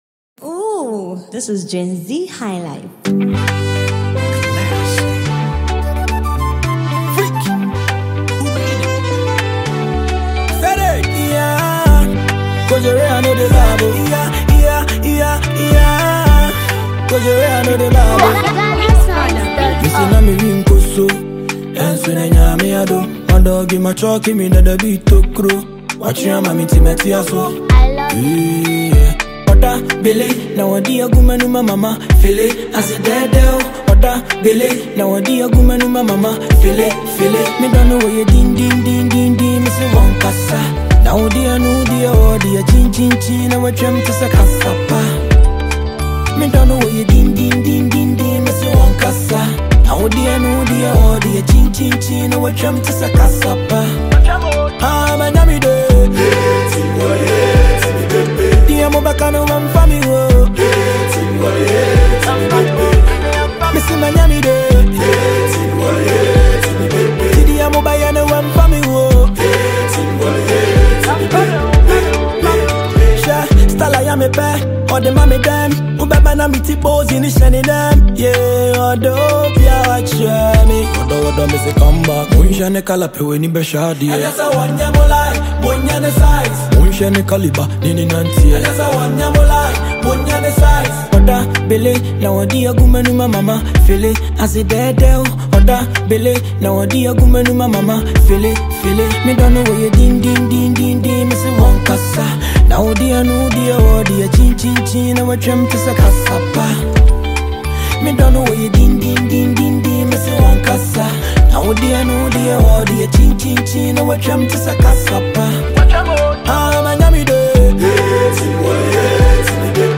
Known for his smooth blend of Afrobeat and street vibes